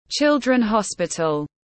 Children hospital /ˈtʃɪl.drən hɒs.pɪ.təl/